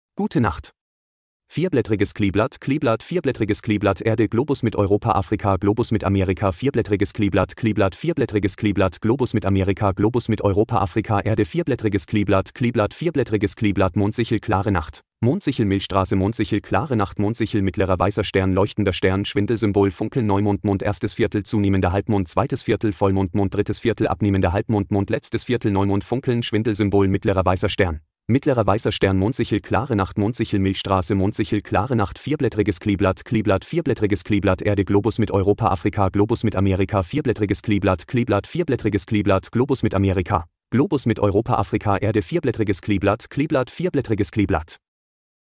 A few years ago, I ran Geekbench on my iPhone 13 Mini as it ran various compute benchmarks, and recorded it with a pair of EM pickup coils. It made some fun noises.